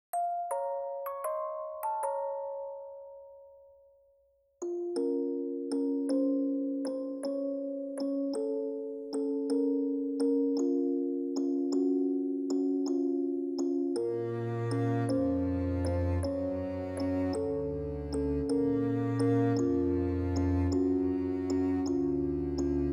added some of the sound effects + go bgm